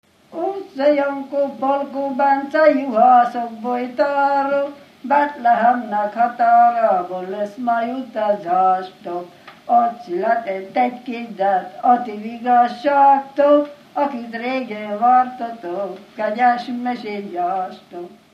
Felföld - Pozsony vm. - Jóka
Műfaj: Mendikás nóta
Stílus: 6. Duda-kanász mulattató stílus
Kadencia: 5 (1) 5 1